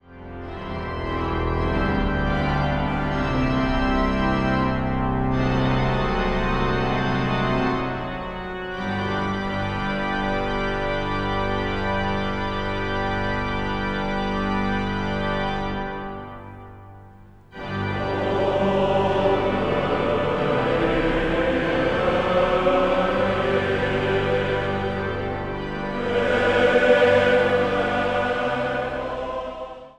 orgel.
Zang | Mannenzang